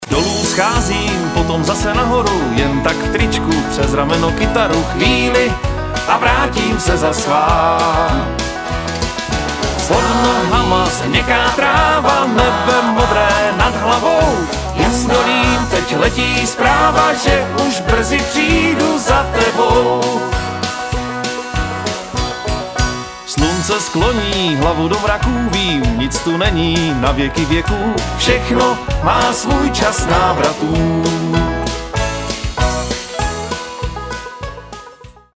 Sólový zpěv